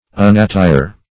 Search Result for " unattire" : The Collaborative International Dictionary of English v.0.48: Unattire \Un`at*tire"\, v. t. [1st pref. un- + attire.] To divest of attire; to undress.